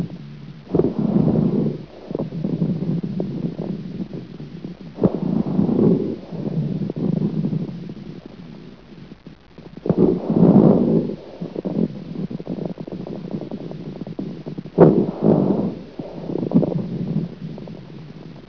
Listen to the lung sounds and try to identify with adventitious breath sounds (Table 32-3 on page 853) and we will discuss in class.
lungsound6.aif